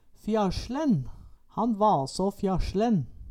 Høyr på uttala Ordklasse: Adjektiv Kategori: Karakteristikk Attende til søk